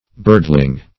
Birdling \Bird"ling\, n.